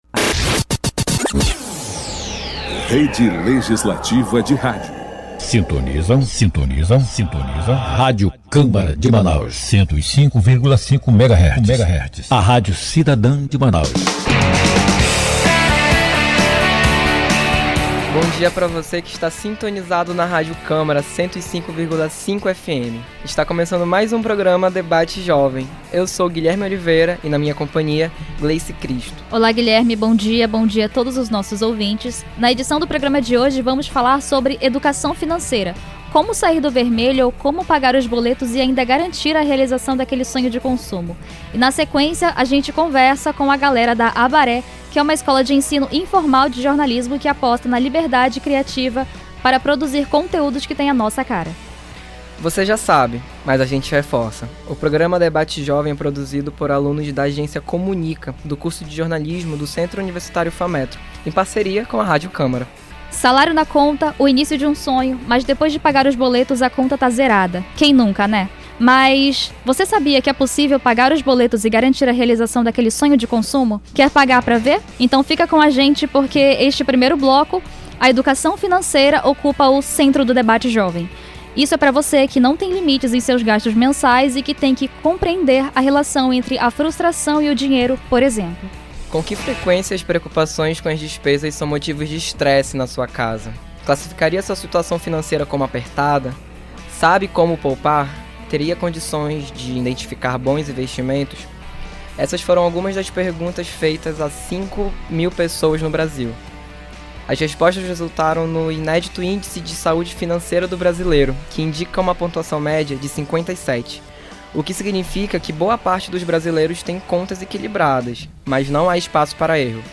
Debate Jovem é um programa realizado pela Agência Experimental de Comunicação do curso de Jornalismo do Centro Universitário Fametro em parceria com Radio Câmara a Rádio Cidadã de Manaus, 105,5 FM.